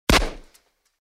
Звуки пистолета
Один выстрел из пистолета